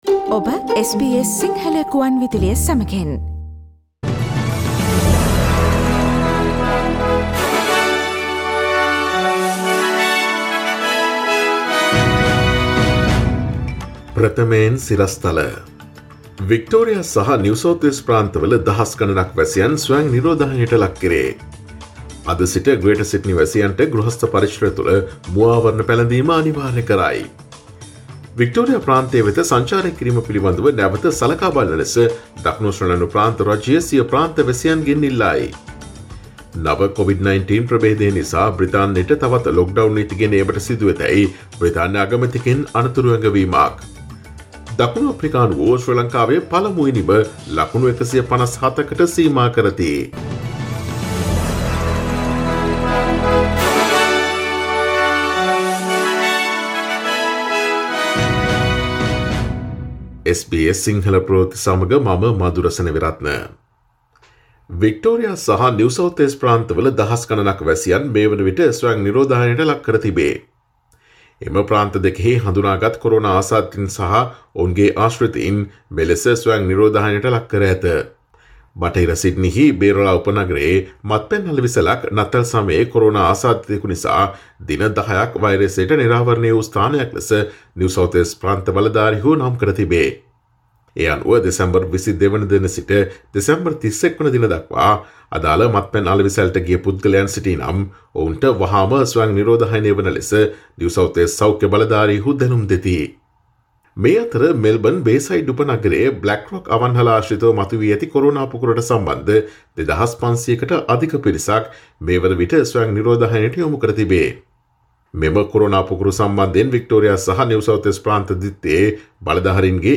Today’s news bulletin of SBS Sinhala radio – Monday 04 January 2021